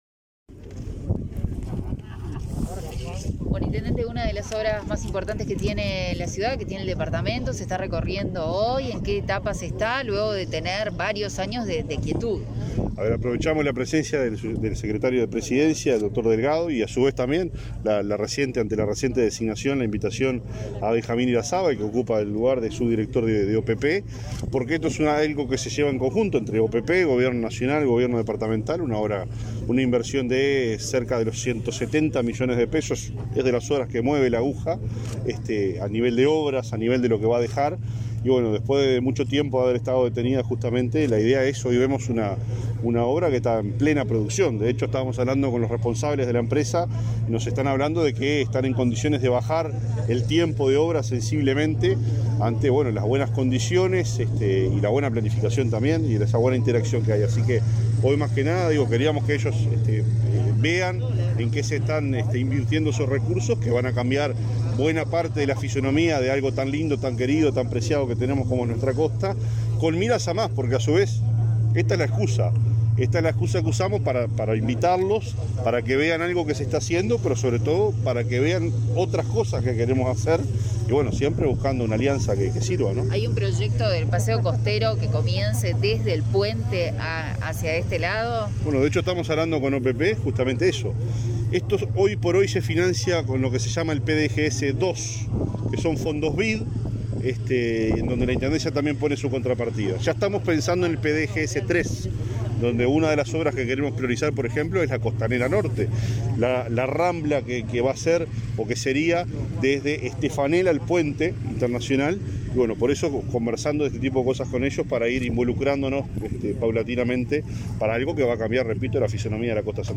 Palabras de Álvaro Delgado, Benjamín Irazábal y Nicolás Olivera 30/06/2021 Compartir Facebook X Copiar enlace WhatsApp LinkedIn Durante la recorrida por las obras en la costanera de Paysandú, brindaron declaraciones a la prensa el secretario de la Presidencia, Álvaro Delgado, el subdirector de la OPP, Benjamín Irazábal, y el intendente local, Nicolás Olivera.